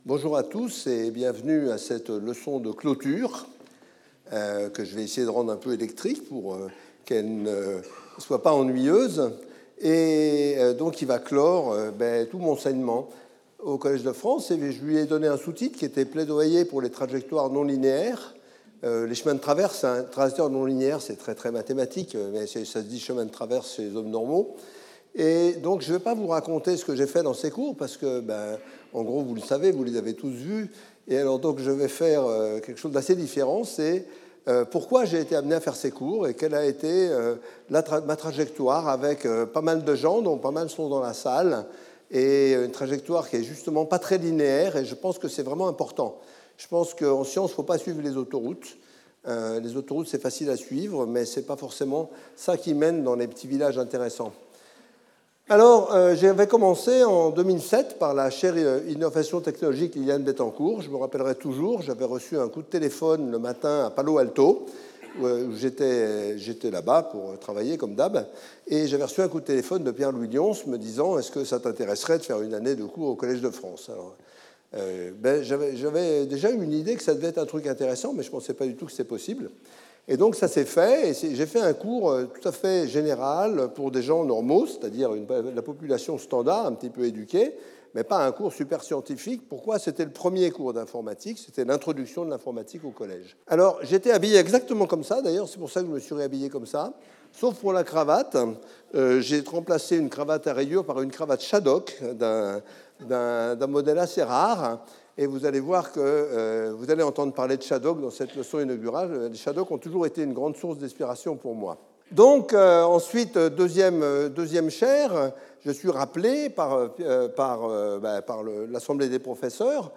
The more festive closing lecture of my chair was an opportunity to recall the main milestones of my research career since 1970 : tIF language for processing and querying files (1970-1973), inversion of recursive program computations (1973-1976), mathematical study of the syntactic and semantic properties of l-calculus and in particular its stability and sequentiality properties (1975-1982), automata theory (1989), asynchronous models, synchronous and vibrational models of parallelism and associated programming languages (1983-), real-time programming in synchronous languages (1983-2009), high-